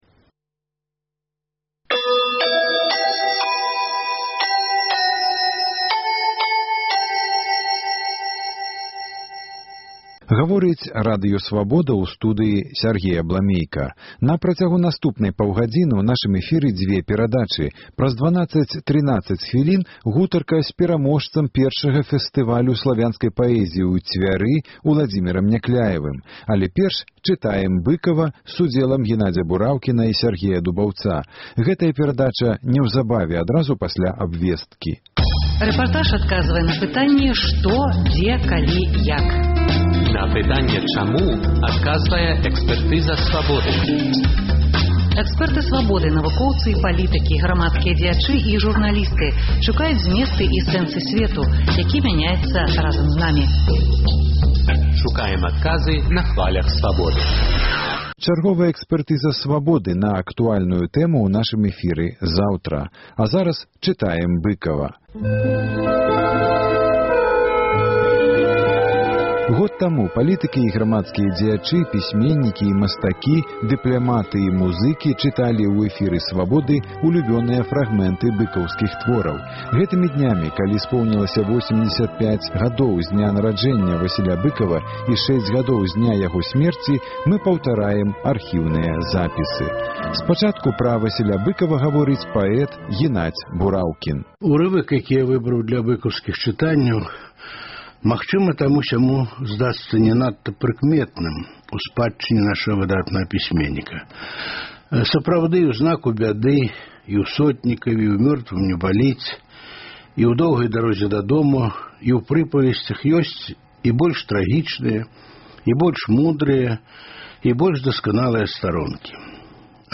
Размова з паэткай Данутай Бічэль пра Васіля Быкава, развагі Аляксандра Фядуты пра кнігу перапіскі Рыгора Барадуліна з маці “Паслаў бы табе душу" і гутарка з Уладзімерам Някляевым і ягоныя новыя вершы ў аўтарскім чытаньні